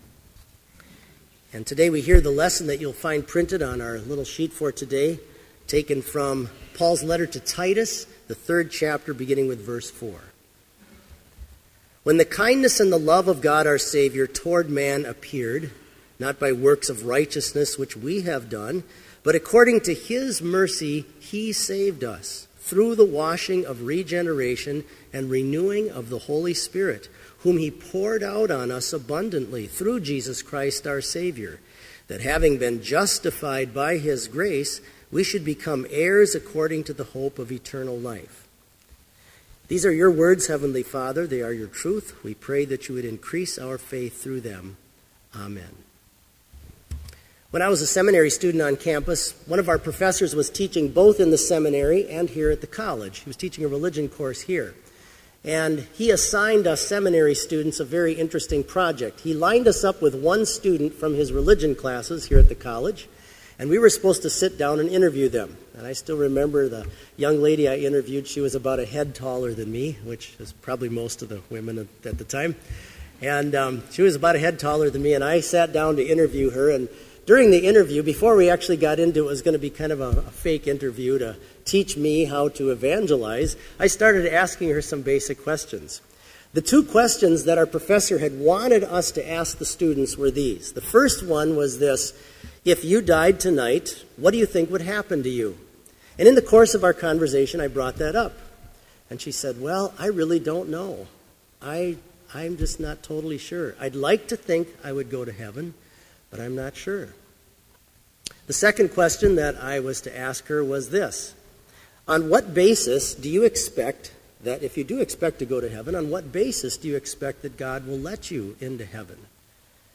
Complete Service
• Prelude
• Hymn 241, He That Believes and Is Baptized
• Devotion
This Chapel Service was held in Trinity Chapel at Bethany Lutheran College on Tuesday, May 12, 2015, at 10 a.m. Page and hymn numbers are from the Evangelical Lutheran Hymnary.